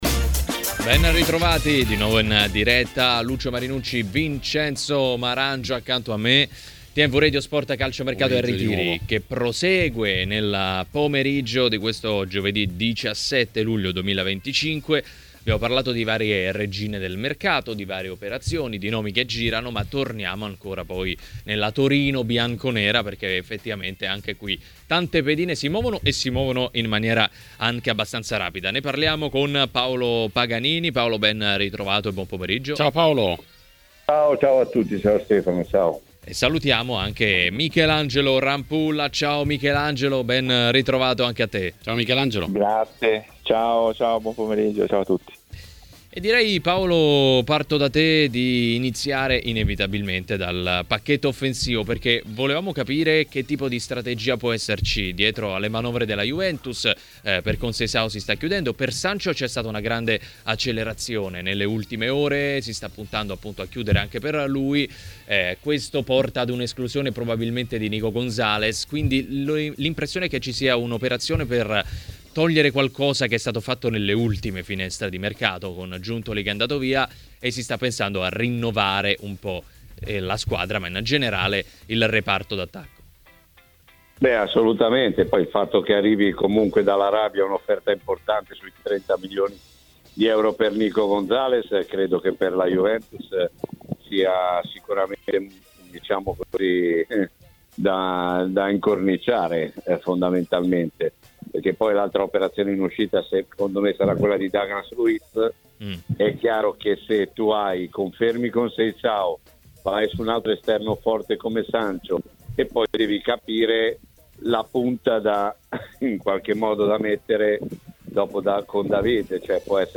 A intervenire a Calciomercato e Ritiri, nel pomeriggio di TMW Radio, è stato l'ex portiere Michelangelo Rampulla.